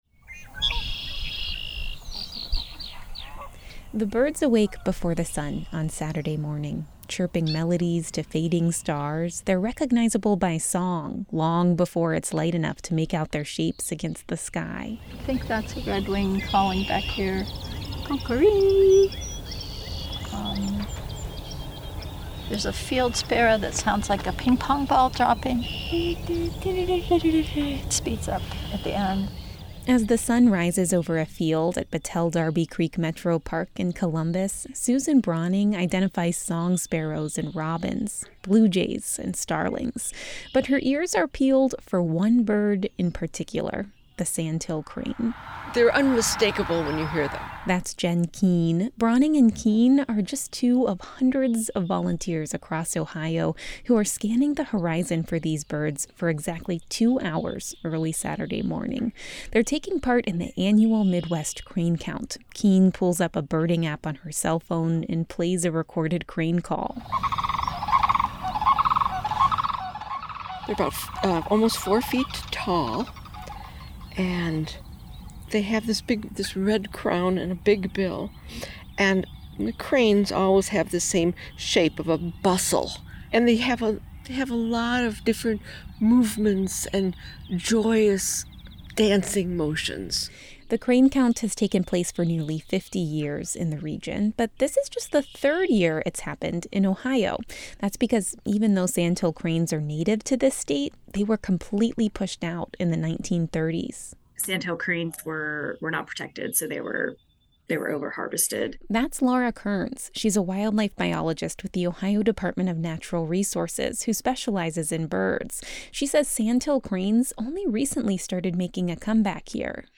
The birds awake before the sun on Saturday morning.
Chirping melodies to fading stars, they’re recognizable by song long before it’s light enough to make out their shapes against the sky.